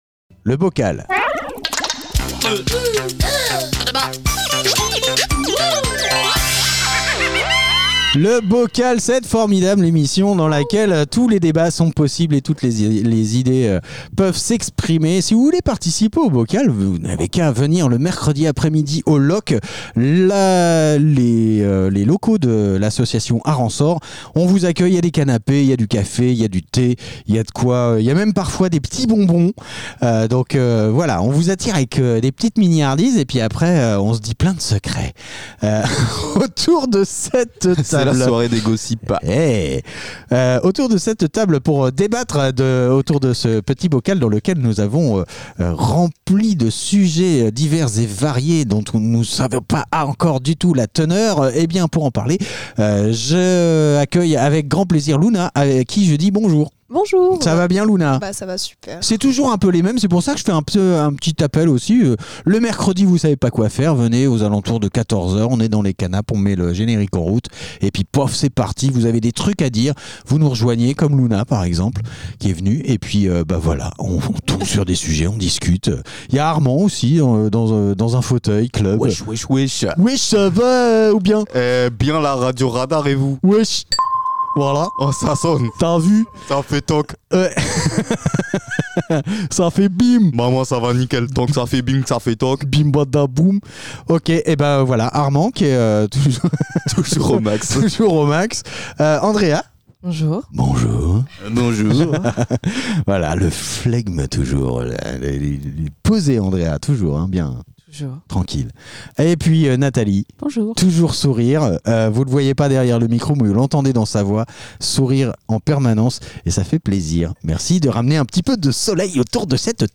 Une poignée d'invités propose des sujets de débats à bulletin secret. Ces petits papiers sont délicatement mélangés pour n'en tirer qu'un seul au sort. Le sujet, une fois dévoilé, donne lieu à des conversations parfois profondes, parfois légères, toujours dans la bonne humeur !